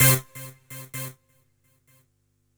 Synth Stab 01 (C).wav